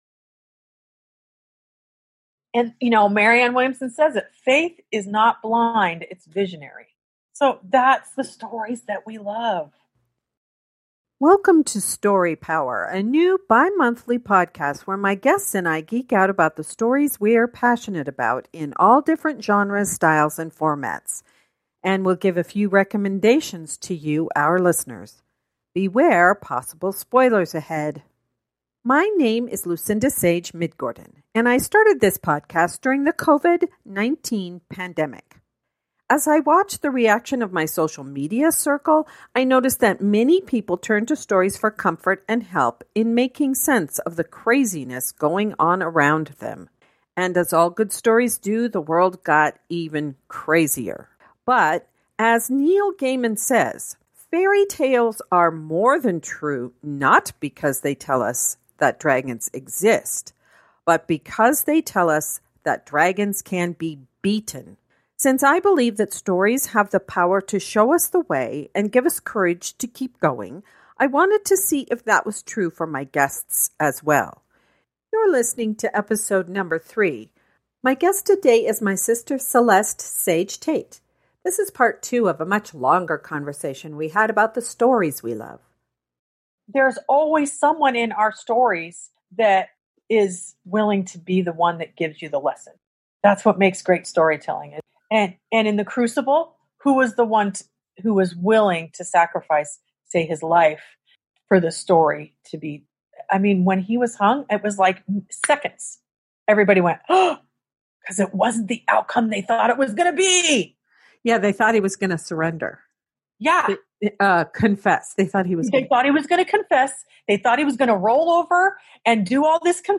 This is part two of my long conversation with my sister